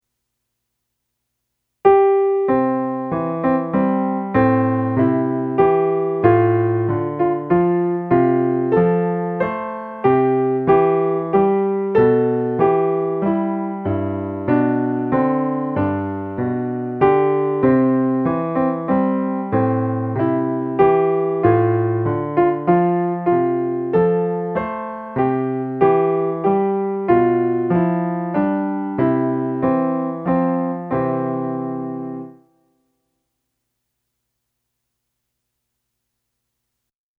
for easy playing by little hands
plus 18 more classic cowboy tunes.